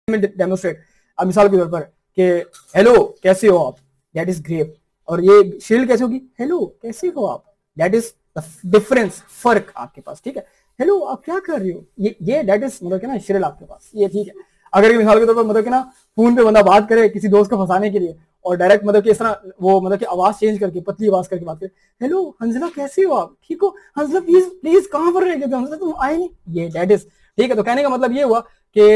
In a captivating display, your teacher transforms into a one-man orchestra, using his voice to illustrate the concept of pitch. With a deep rumble, he mimics the low growl of a bass, then soars to a high-pitched chirp like a playful bird. By dramatically shifting his vocal tones, he demonstrates how pitch changes along with the frequency of sound waves.